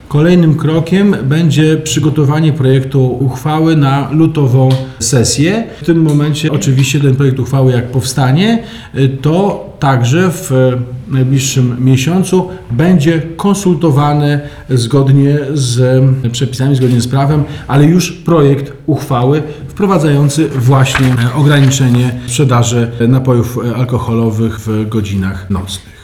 We wtorek, 13 stycznia, w płockim ratuszu odbyła się konferencja prasowa poświęcona wynikom konsultacji społecznych dotyczących nocnej sprzedaży alkoholu na terenie miasta.
– dodaje prezydent Nowakowski.